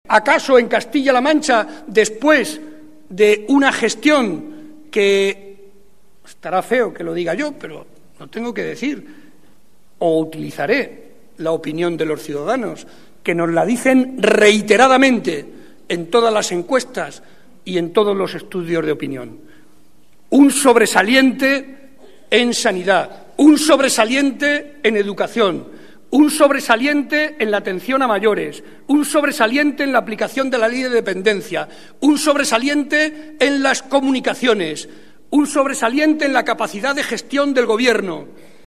En el acto de celebración del centenario de la Agrupación de Puertollano